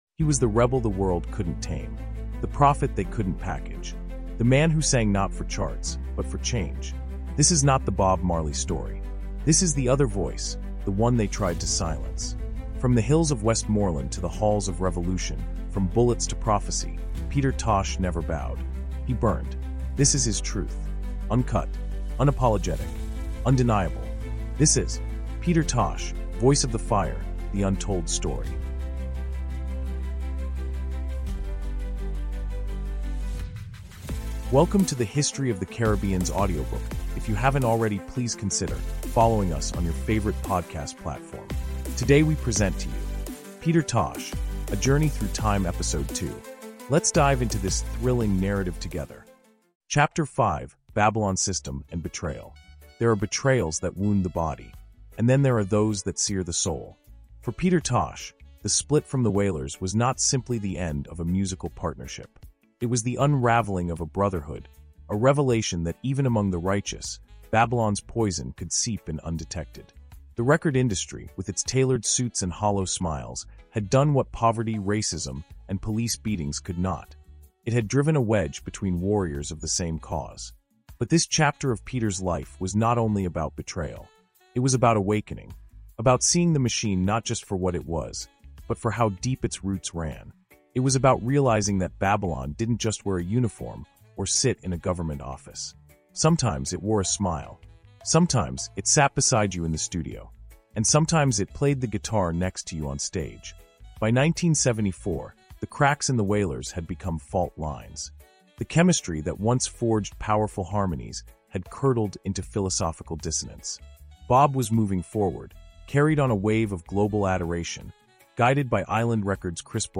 Peter Tosh: Voice of the Fire – The Untold Story is a gripping 16-chapter cinematic audiobook that resurrects the life, music, and martyrdom of one of reggae’s fiercest revolutionaries. From his rise with The Wailers to his assassination in 1987, this raw and emotionally charged narrative exposes the industry sabotage, political conspiracies, and spiritual battles behind the man who refused to bow. Featuring detailed storytelling written for AI narration, this audiobook is more than a biography—